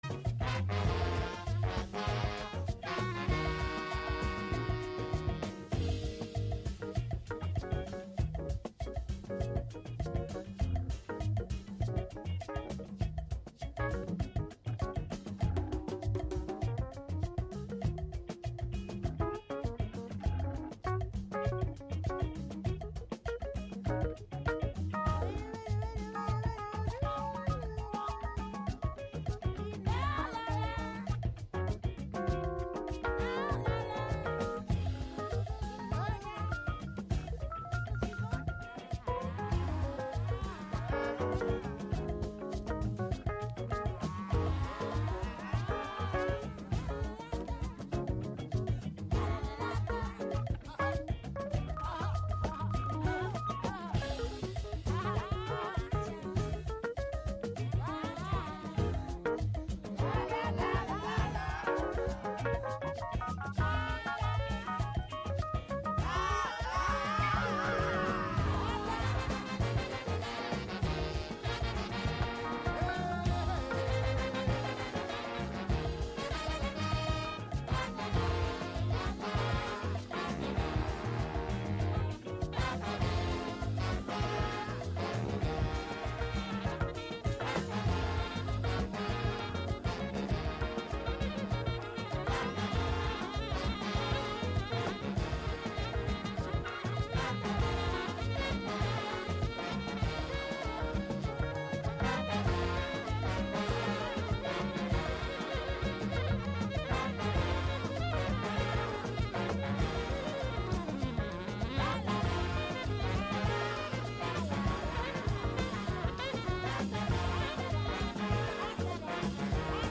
Show includes local WGXC news at beginning and midway through.